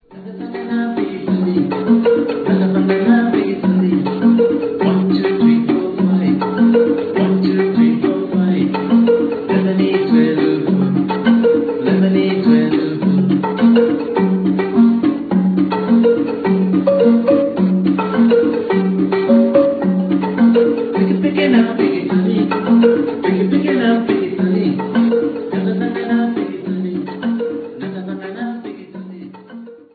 thumb piano, marimba and drums
a counting song in Bemba
well recorded and well played.